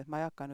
Poolvokaal /j/ vokaalide vahel.